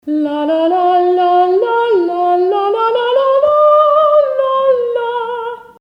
Gesang
Gesang.mp3